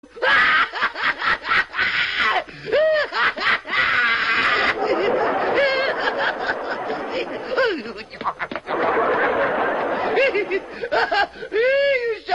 Risada Quico (chaves)